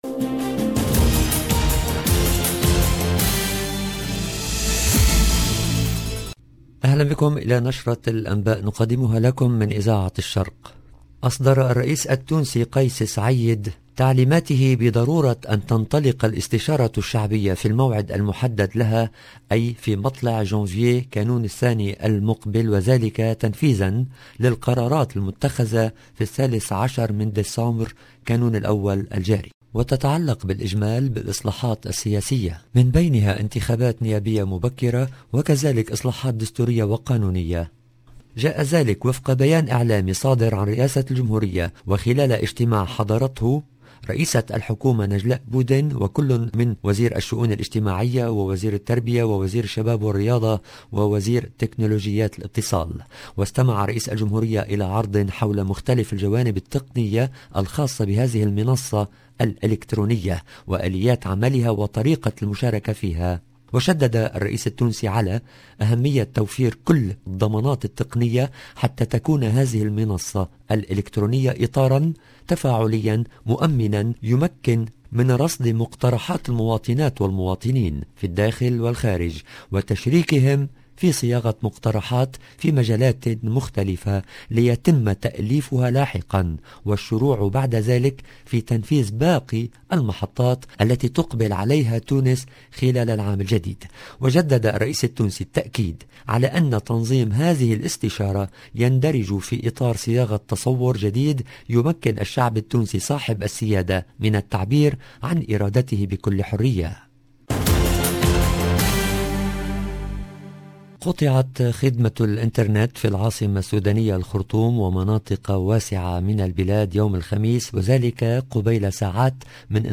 LE JOURNAL DU SOIR EN LANGUE ARABE DU 30/12/21